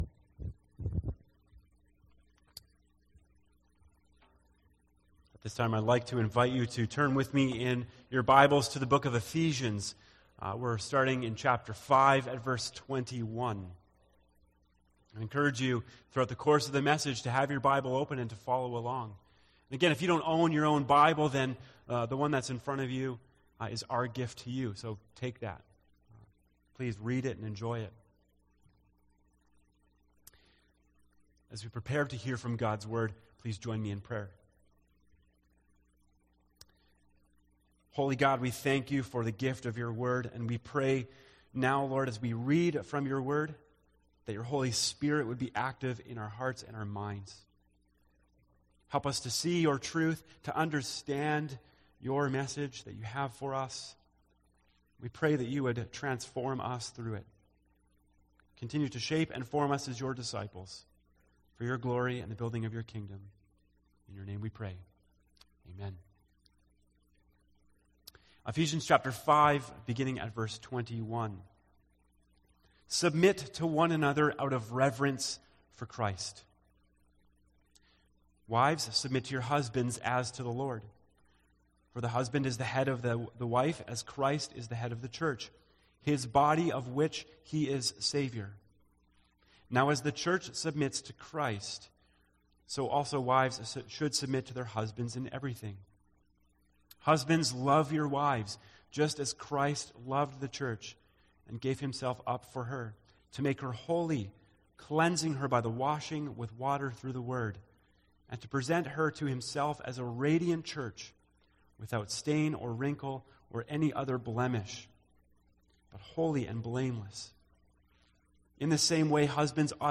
A message from the series "Withness."